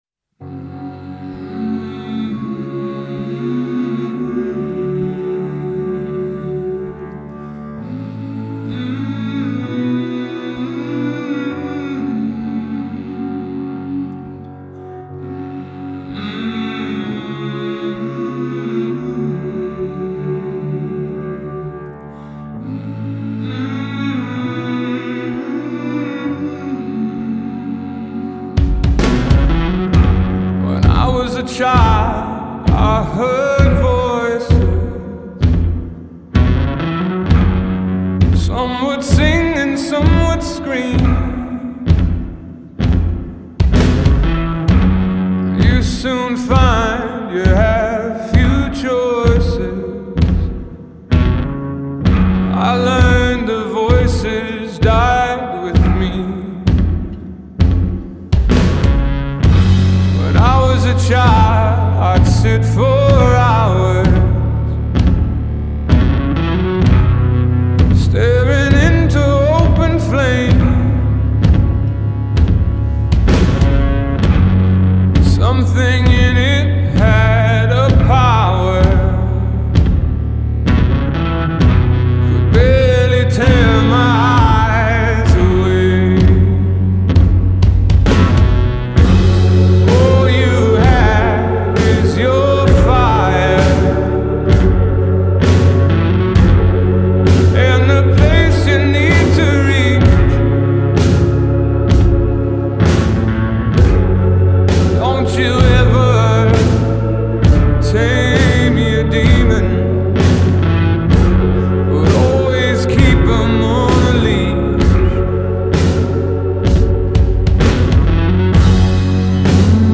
ирландский певец и композитор